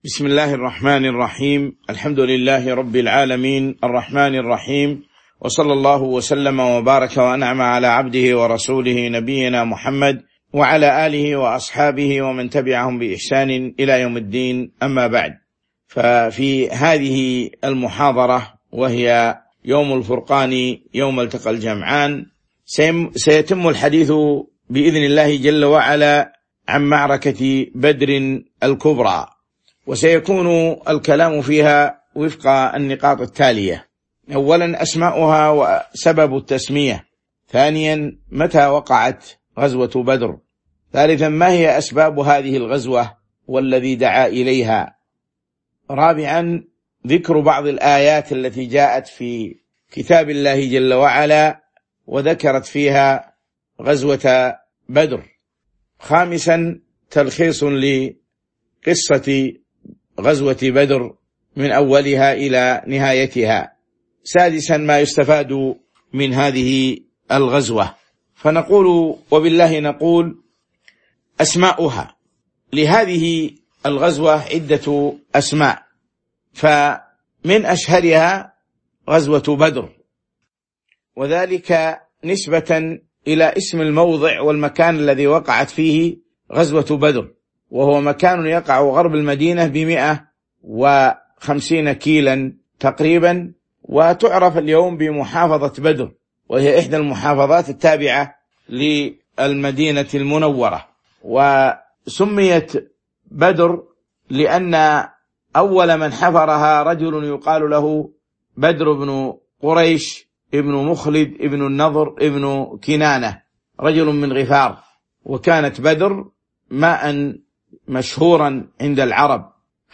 تاريخ النشر ١٧ رمضان ١٤٤٣ هـ المكان: المسجد النبوي الشيخ